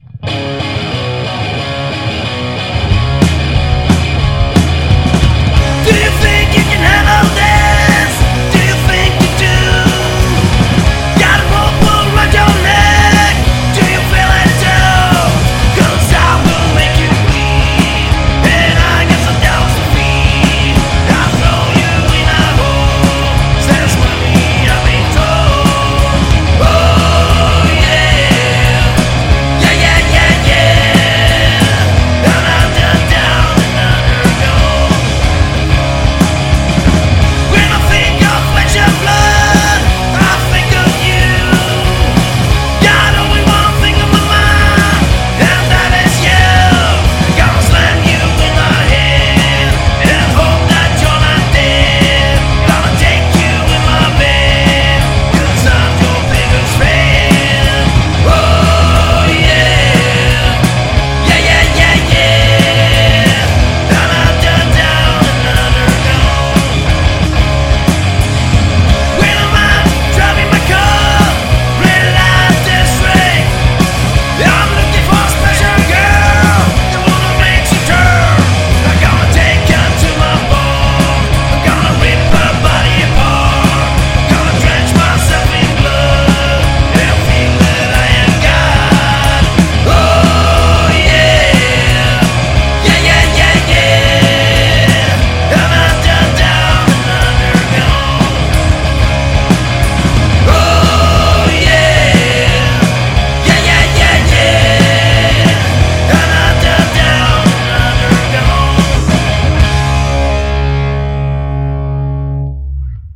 Inga effekter men ni hör väl ungefär hur det låter